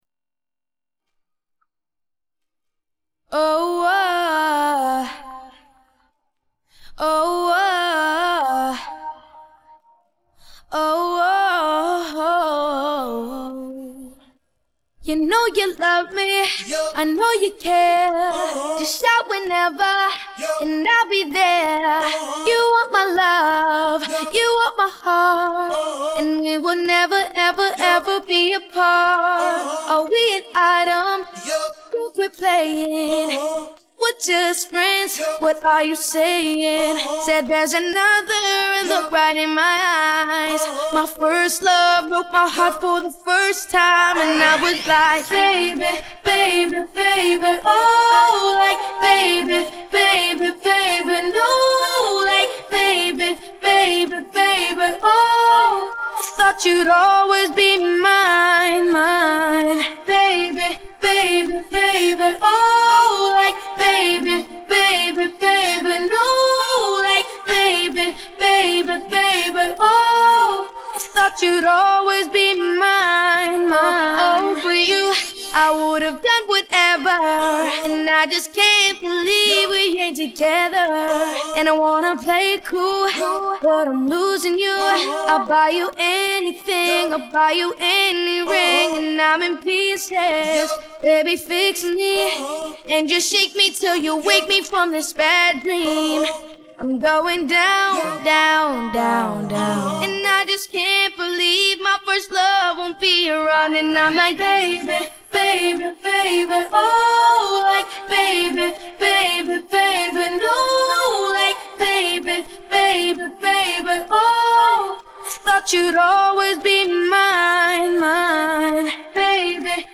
Vocal Part